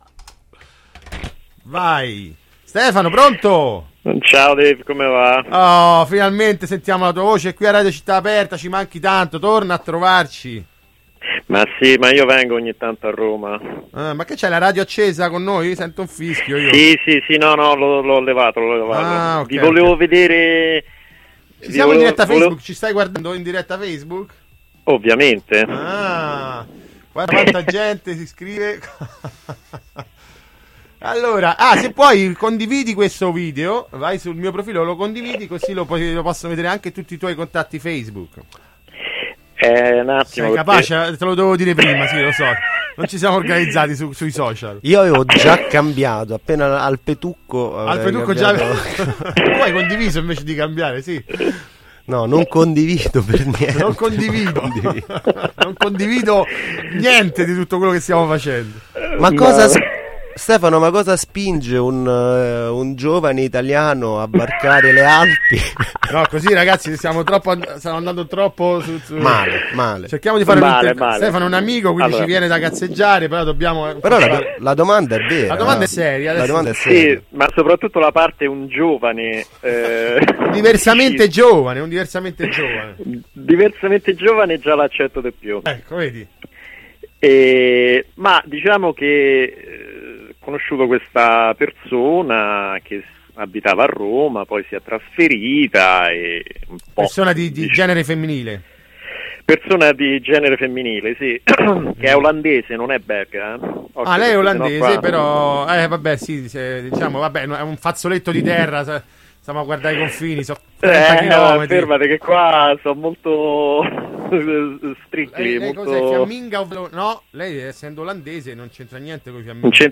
Intervista
Chiacchierata telefonica